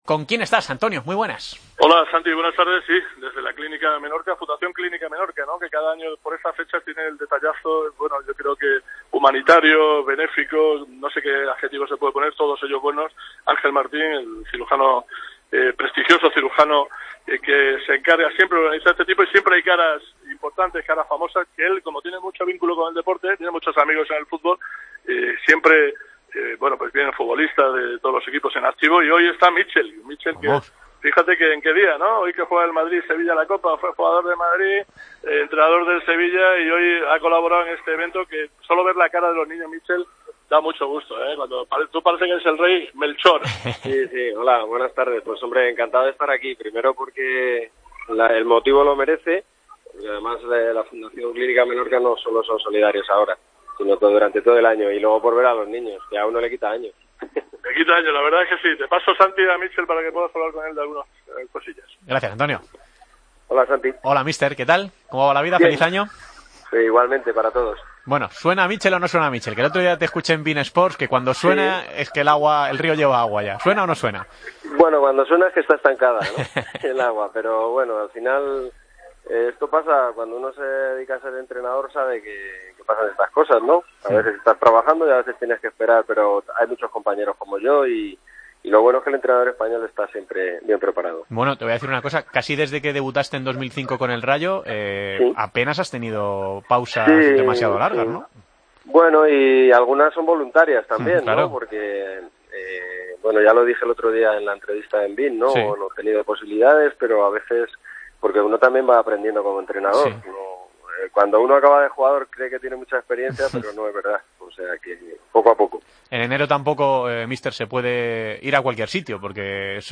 Hablamos con el exjugador del Real Madrid y exentrenador del Sevilla, horas antes del duelo entre ambos equipos: "Cuando uno es entrenador sabes que siempre estás en las quinielas.